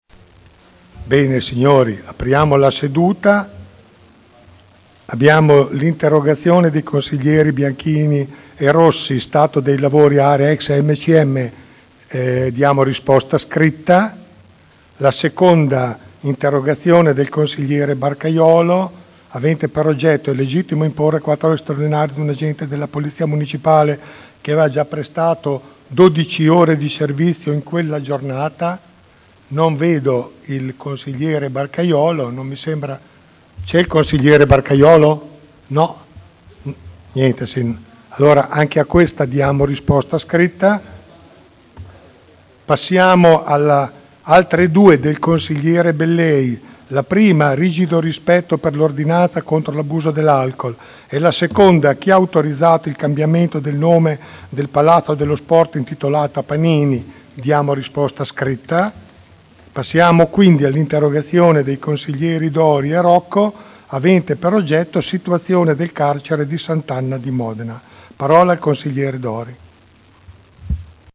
Seduta del 18/07/2011. Il Presidente Pellacani apre il lavori con le interrogazioni.